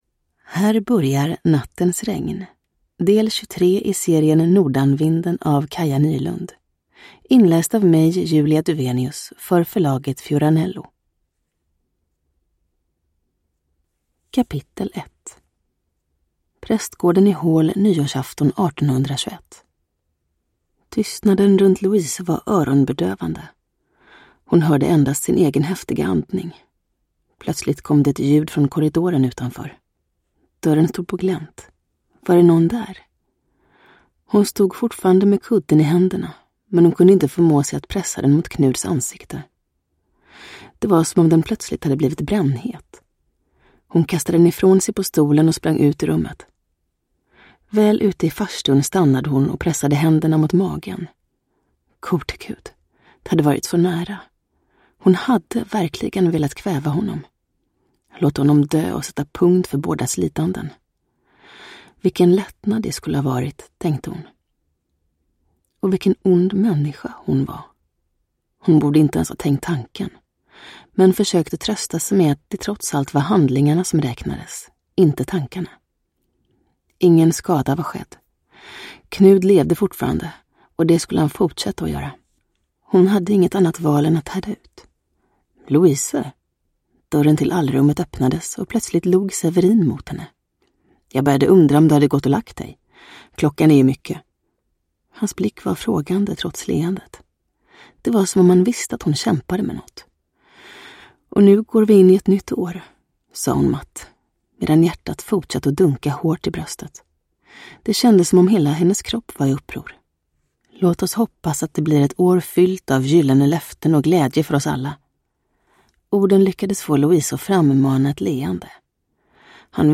Uppläsare: Julia Dufvenius
Ljudbok